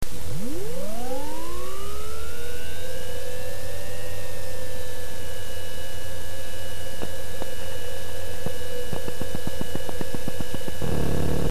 再々度騒音測定（あくまでも素人計測だってば）
※あまりにも録音時の音量が小さいので、ソフト側で音量を２０倍に上げてます。
＜アルミのお弁当箱（前後スポンジ変更後＋スポンジ穴埋め＋スポンジ接着Ｌ字型フタ使用）＞
06-noize-test_seionbox-lks.wav